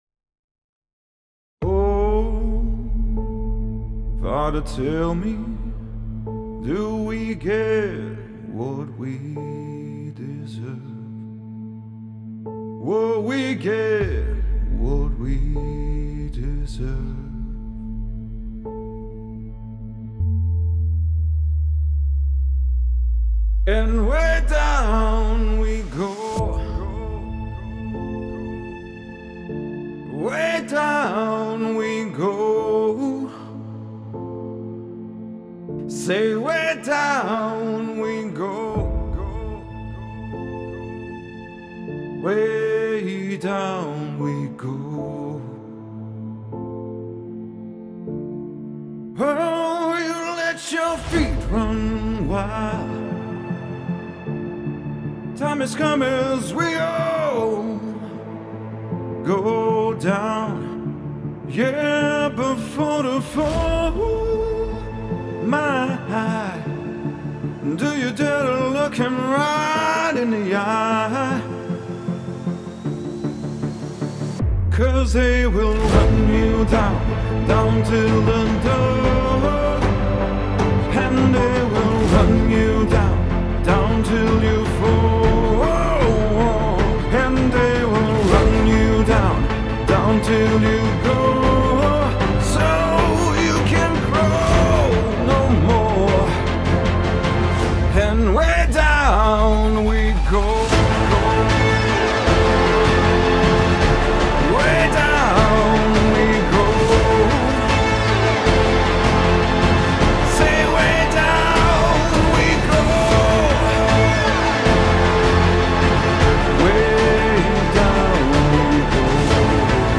ریمیکس هیجانی حماسی